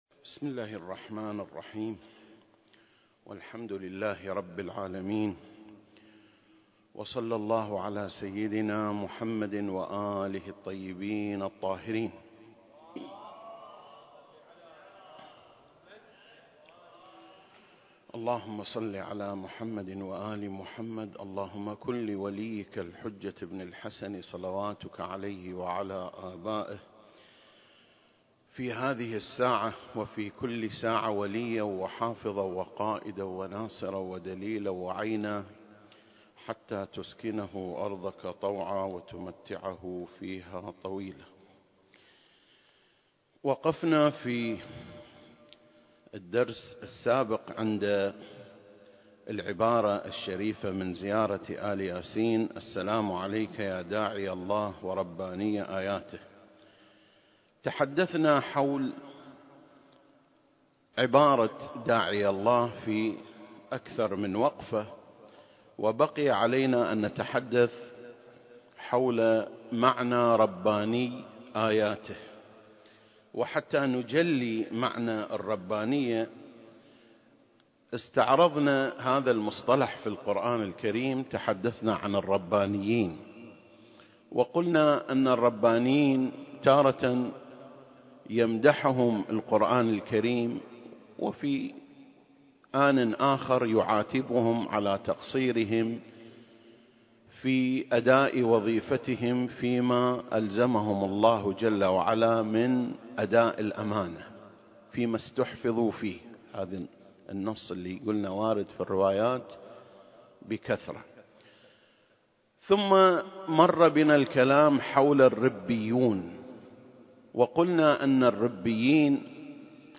سلسلة: شرح زيارة آل ياسين (32) - رباني آياته (2) المكان: مسجد مقامس - الكويت التاريخ: 2021